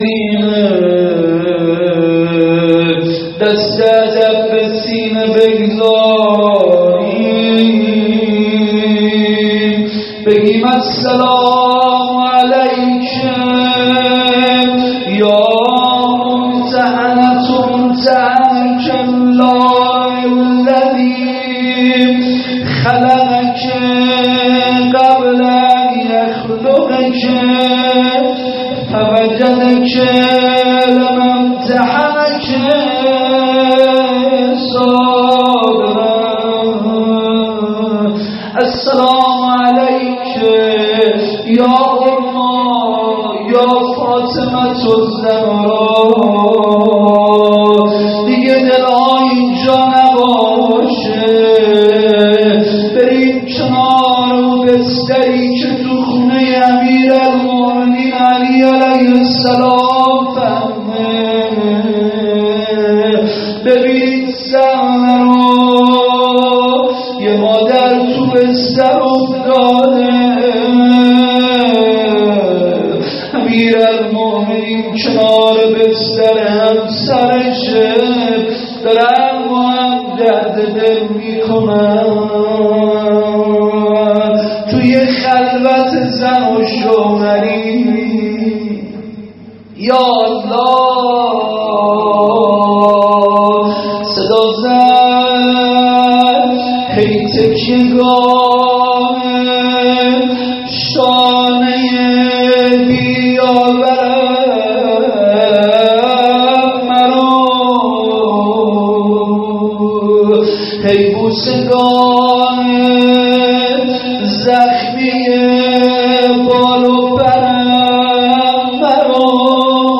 روضه روز شهادت حضرت زهرا سلام الله علیها۹۵